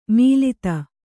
♪ mīlata